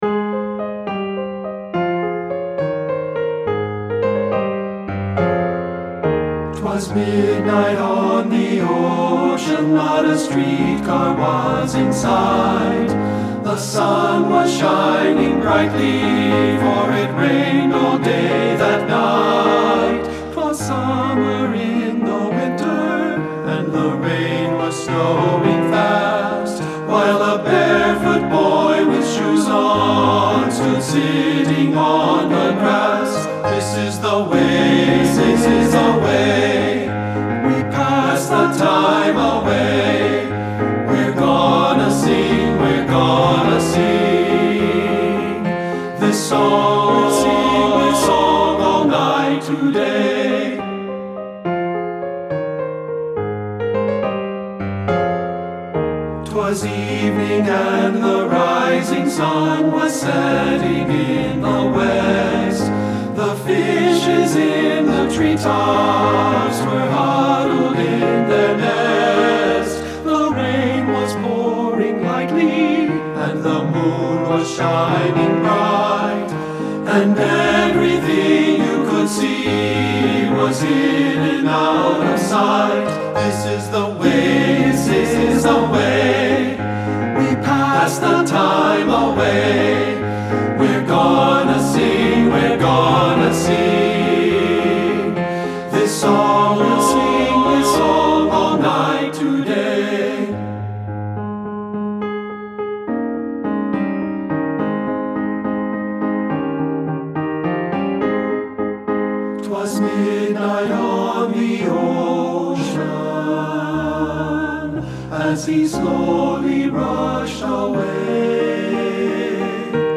T(T)B Voices with Piano
• Tenor
• Bass
• Piano
Studio Recording
Ensemble: Tenor-Bass Chorus
Accompanied: Accompanied Chorus